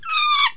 affe3.wav